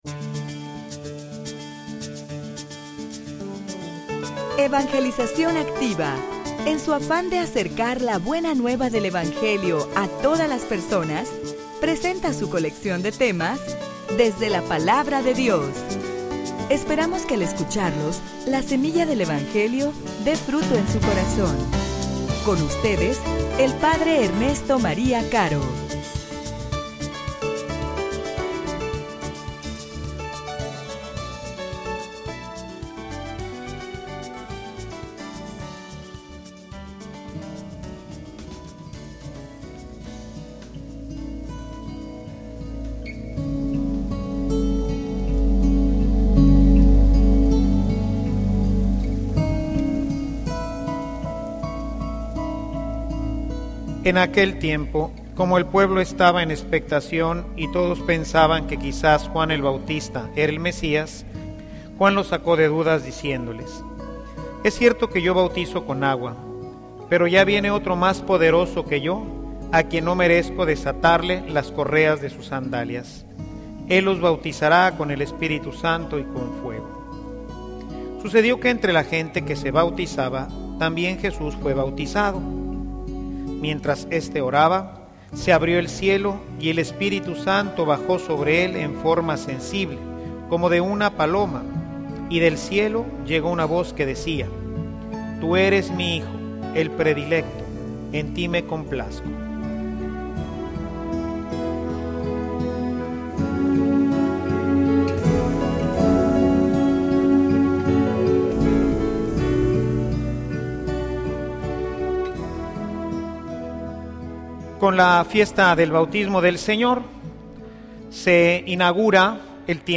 homilia_Con_el_poder_del_Espiritu_Santo.mp3